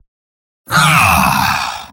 Robot-filtered lines from MvM. This is an audio clip from the game Team Fortress 2 .
Spy_mvm_negativevocalization01.mp3